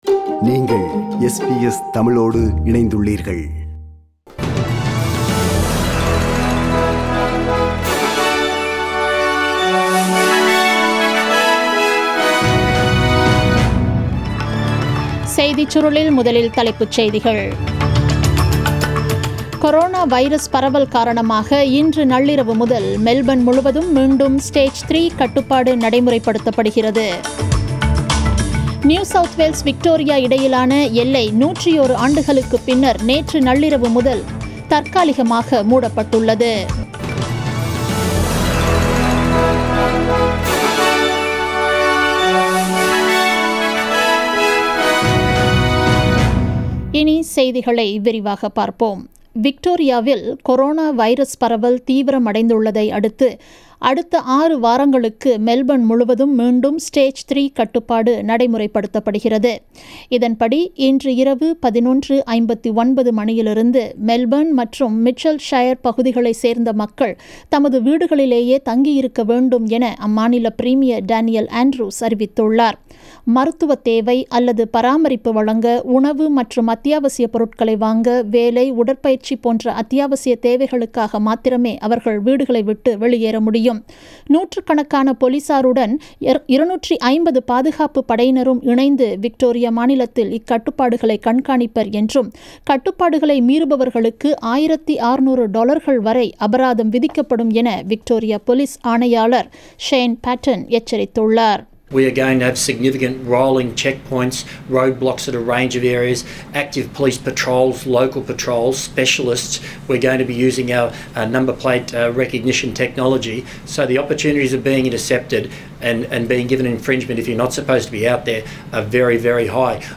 The news bulletin aired on 08 July 2020 at 8pm